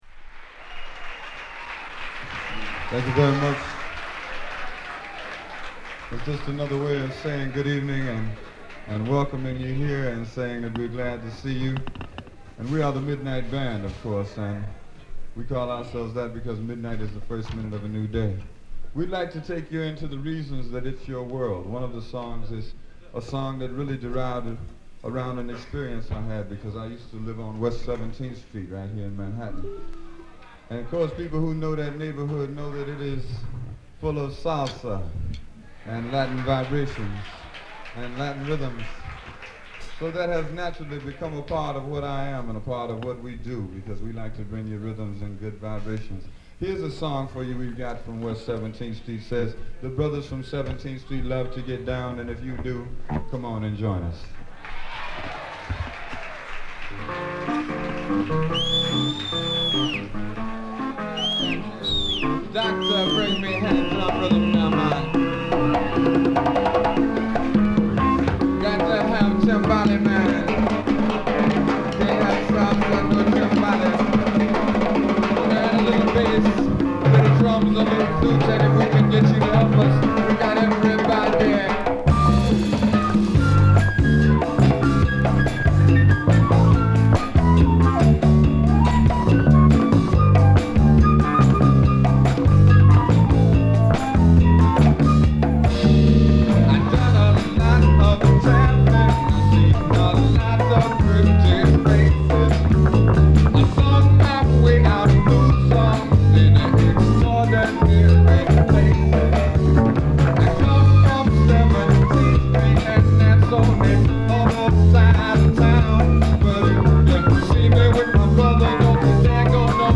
怒濤のパーカッショングルーヴで幕を開け
熱いライブヴァージョンを収録